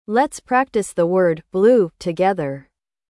O IPA (alfabeto fonético internacional) para a palavra é /bluː/.
• O “bl” tem som forte, como em “black”.
• O “u” é longo, parecido com o “u” em “rule”.
• A palavra rima com “glue”, “true”, “clue”.